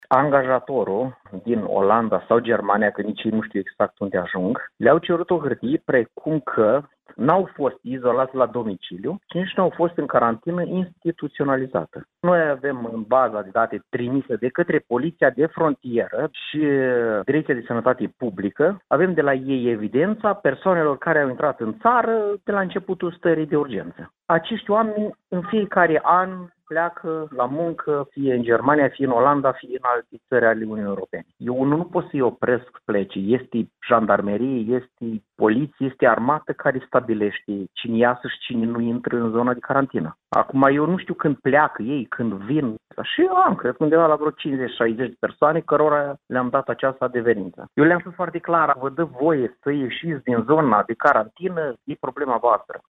Primarul comunei Pătrăuţi, Adrian Isepciuc, a declarat pentru postul nostru de radio, că a eliberat astfel de documente, solicitate de angajatori, pentru aproximativ 60 de persoane, care nu figurau în baza de date a DSP, şi că nu poate şti dacă acestea au părăsit localitatea.